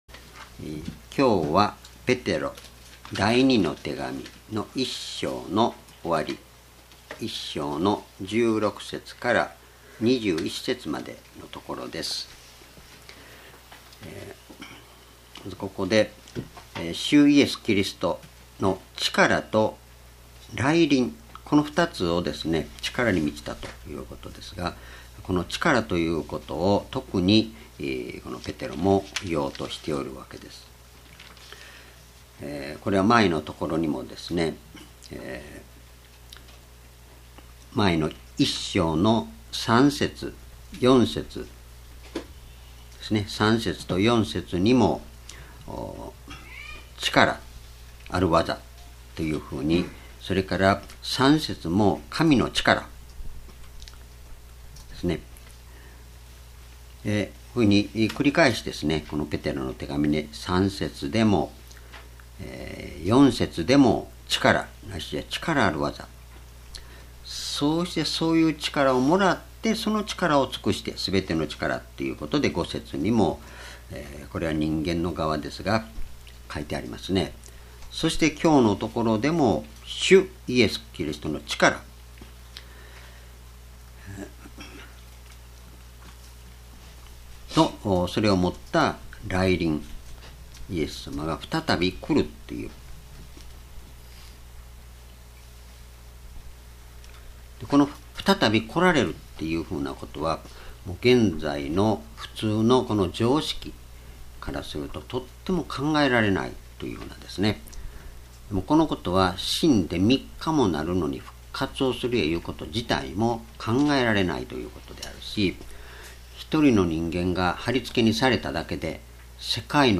主日礼拝日時 ２０１５年１０月１１日 聖書講話箇所 ペテロの手紙二 1章16-21 「明けの明星が心に上るまで」 ※視聴できない場合は をクリックしてください。